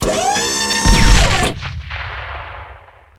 cannon.ogg